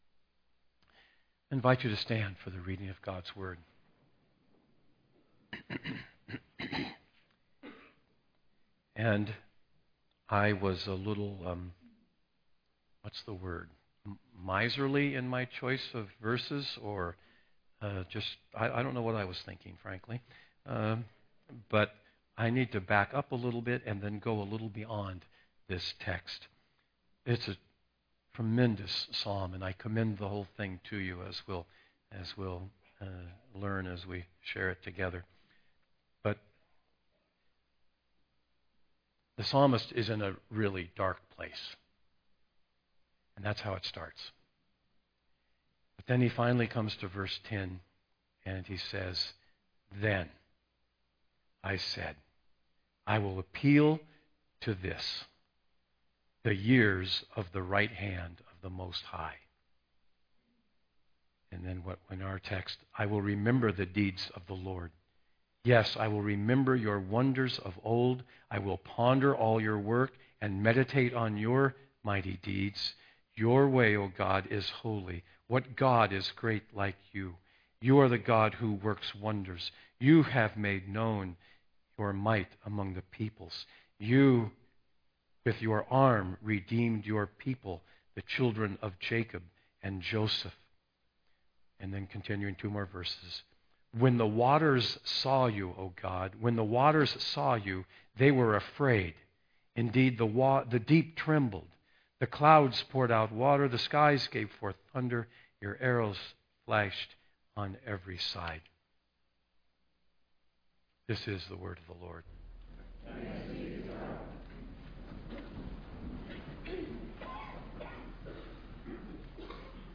Text for the Sermon: Psalm 77:11-15